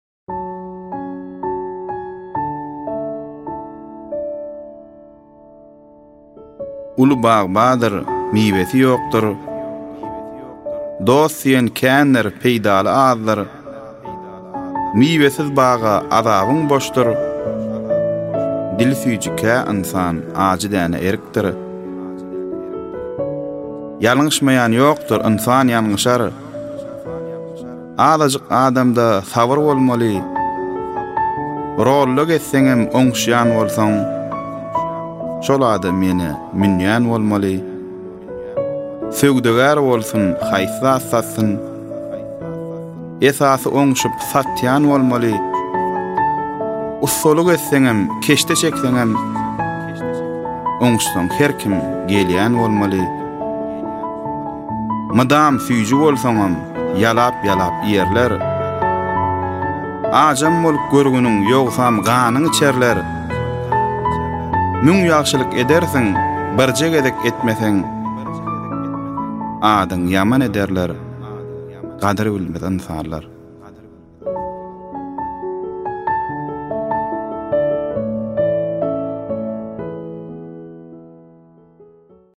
Goşgy